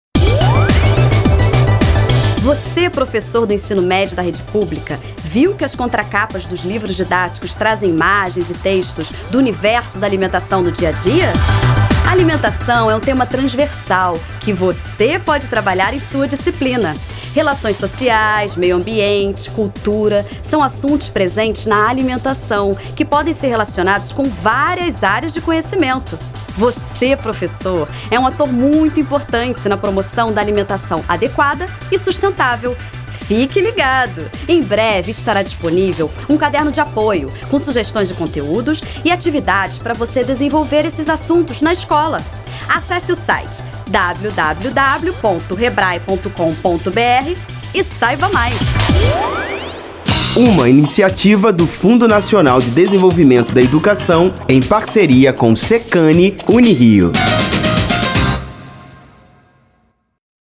Spot de rádio - Capas do Ensino Médio e material de apoio